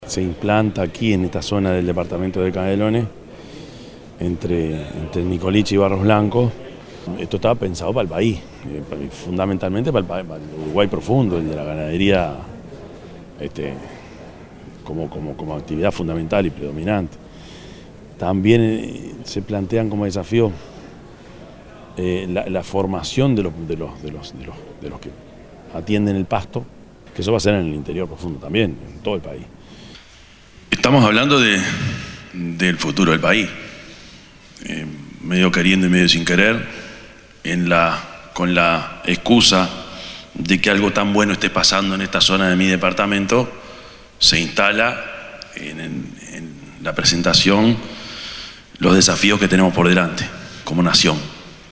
yamandu_orsi_intendente_de_canelones_4.mp3